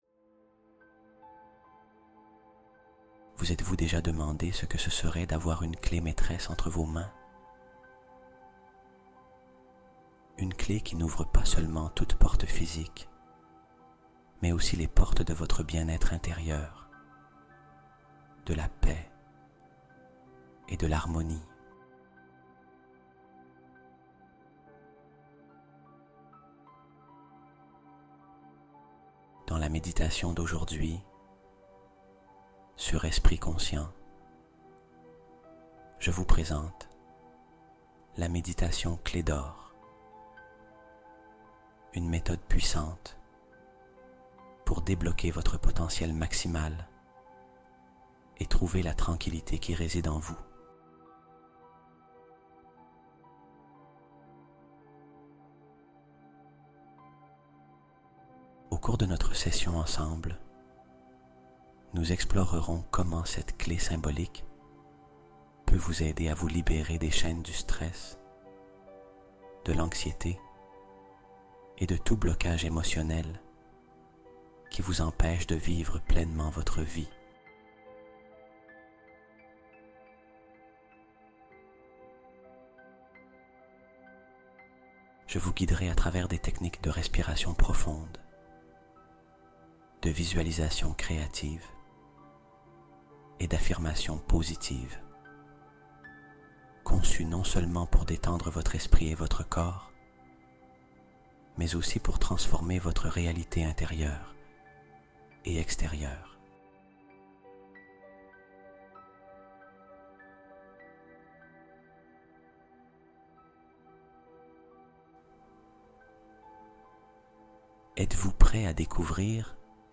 Bond quantique en 8 heures | Hypnose nocturne qui transforme ta réalité